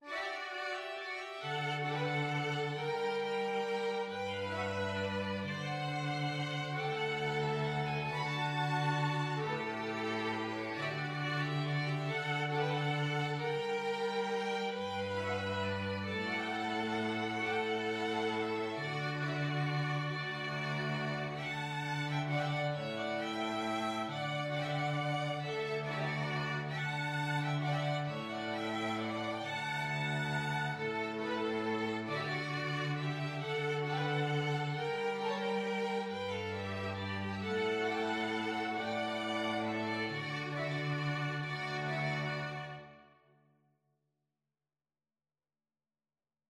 Free Sheet music for Flexible Mixed Ensemble - 3 Players
FluteAccordion (Lead Sheet)
Violin
Cello
Traditional Music of unknown author.
3/4 (View more 3/4 Music)
One in a bar .=45
D major (Sounding Pitch) (View more D major Music for Flexible Mixed Ensemble - 3 Players )